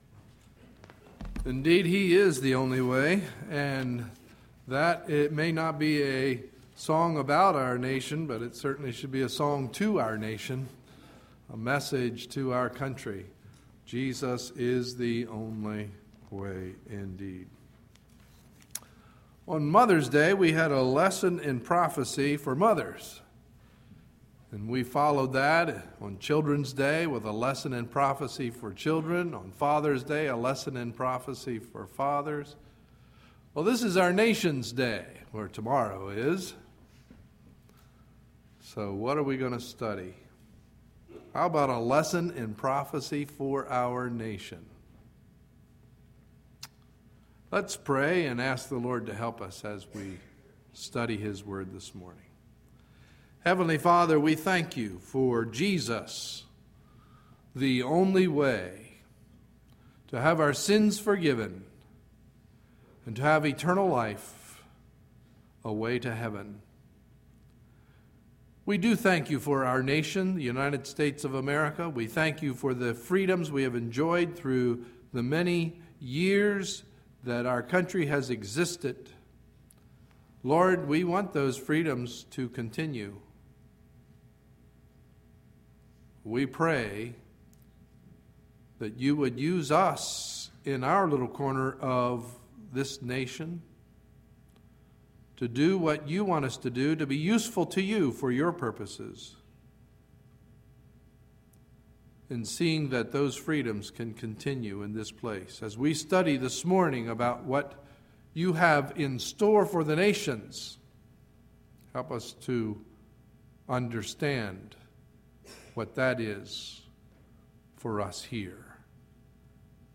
Sunday, July 3, 2011 – Morning Message